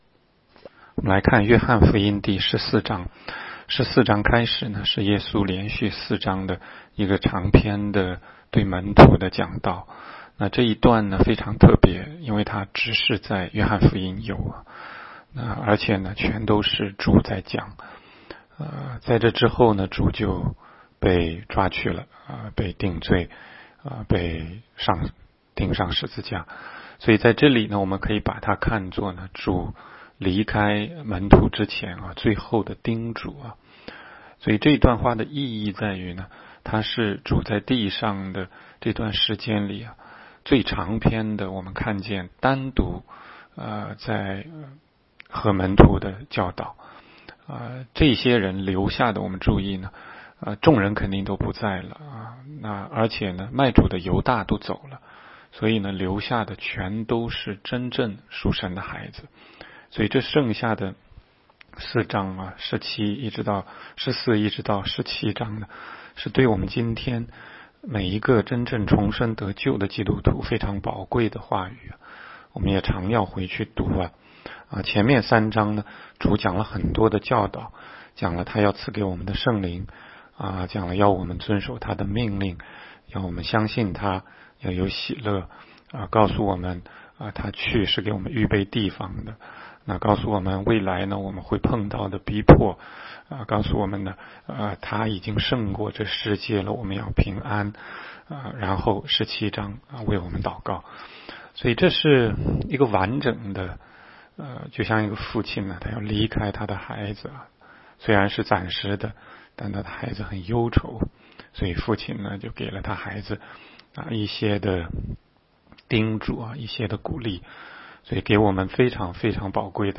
16街讲道录音 - 每日读经-《约翰福音》14章